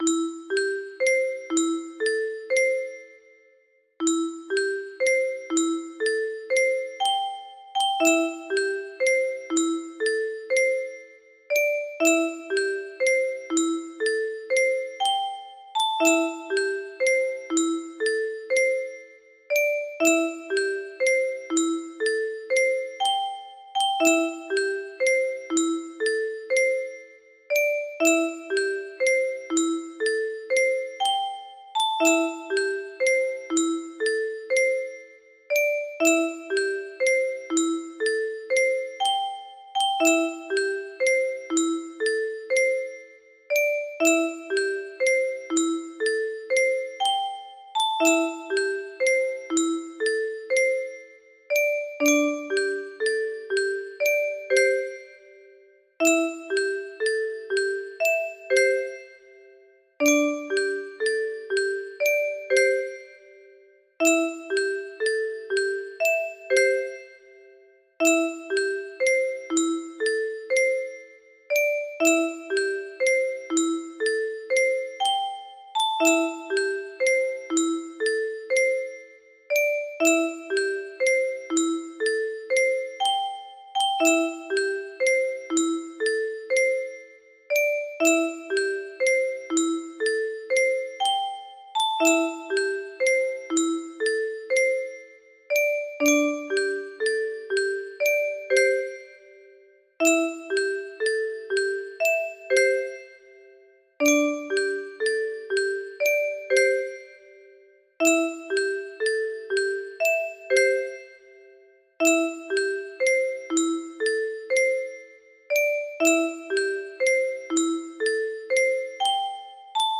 Dreaming music box melody